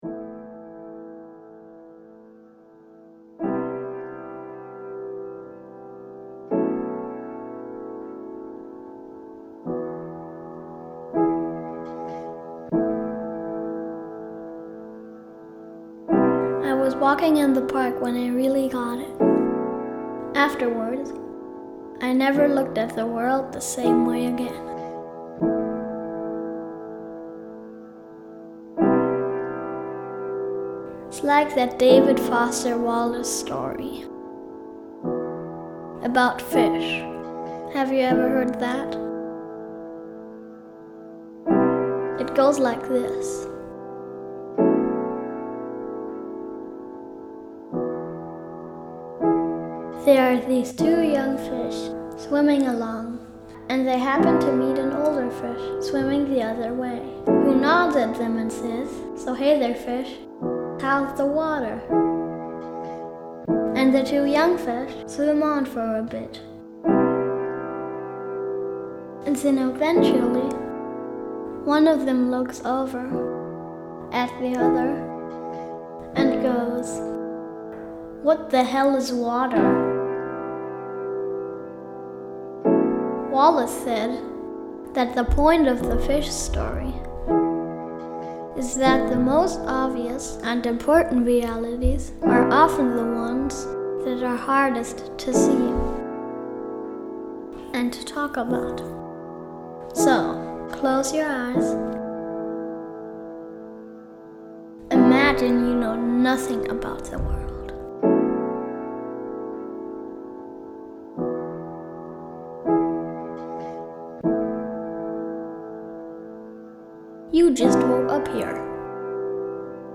But if you're pressed for time, my son and I made an 11 minute audio meditation for you that covers the important stuff. Perception (Memories and Possibilities) [11 min] Go to a park, and pick up a stone.